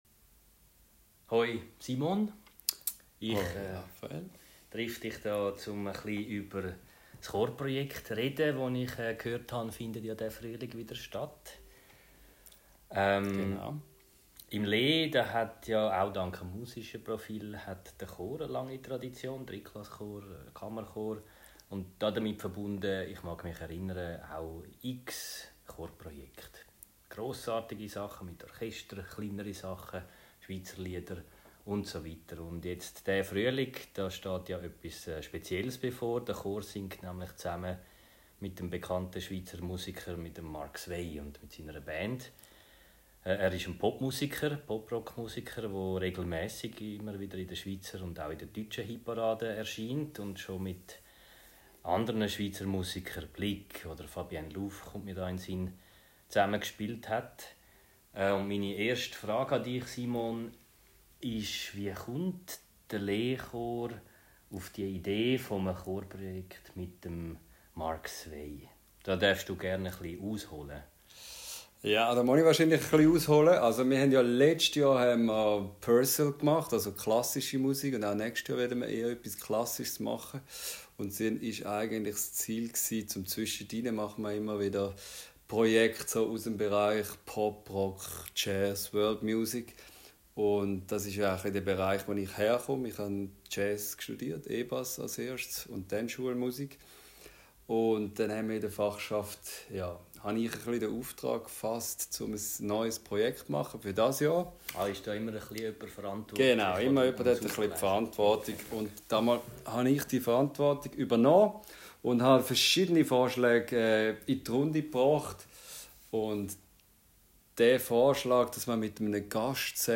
Kurzgespräch